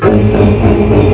Intermèdes Musicaux [cliquez pour écouter] 12.5 ko